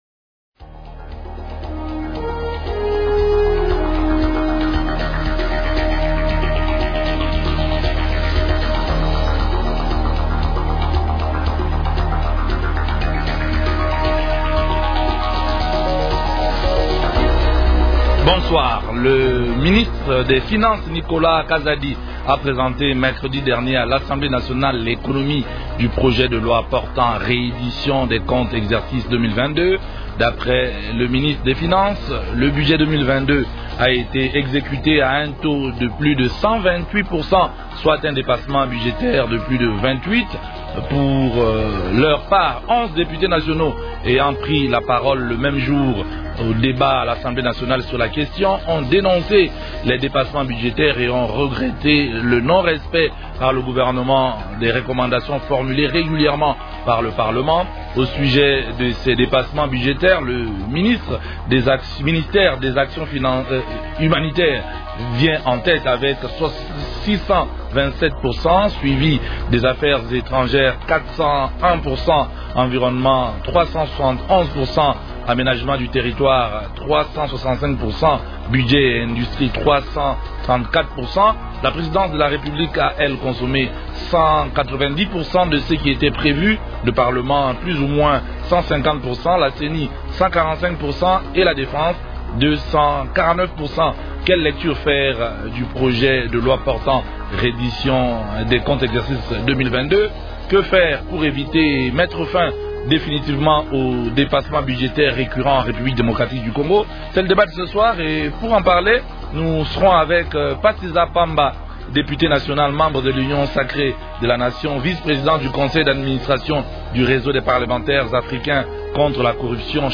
Invités : -Pasi Za Pamba, député national, membre de l’Union sacrée de la nation.